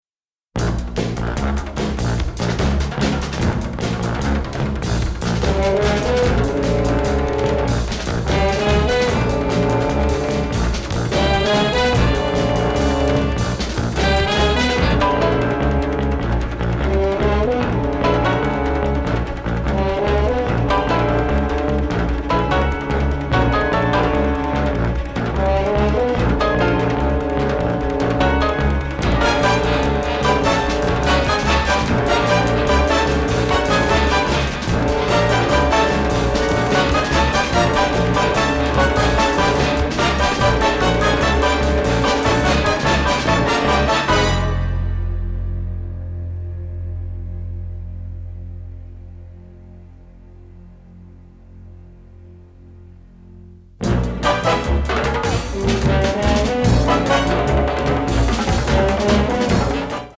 funk/jazz/groove